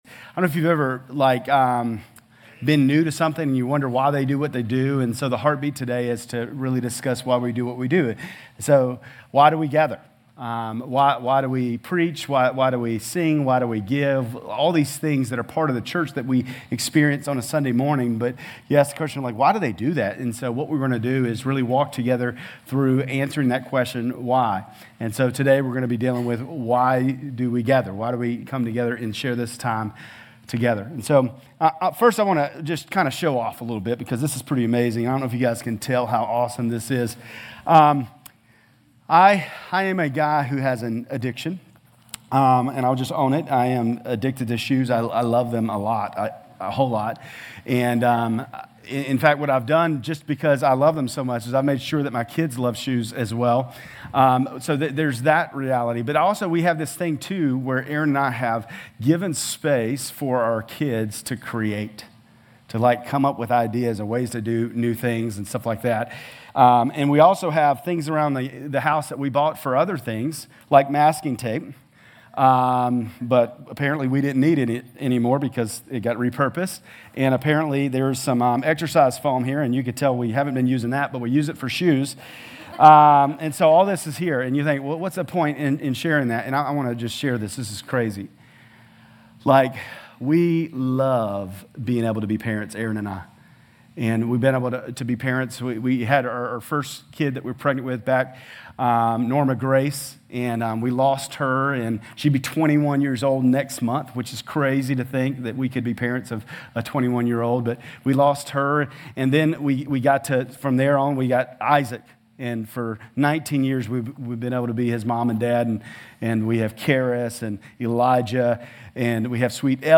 GCC-Lindale-August-27-Sermon.mp3